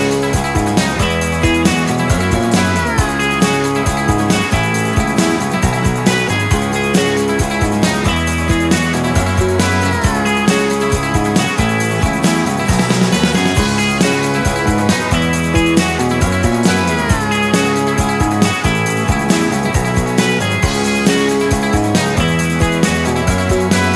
no Backing Vocals Indie / Alternative 3:35 Buy £1.50